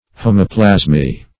Search Result for " homoplasmy" : The Collaborative International Dictionary of English v.0.48: Homoplasmy \Ho"mo*plas`my\, n. [Homo- + Gr.
homoplasmy.mp3